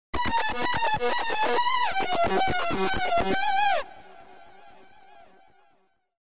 VSTi gitarový syntetizér
Ovladaju sa priamo audio signalom z gitary.
synth4.mp3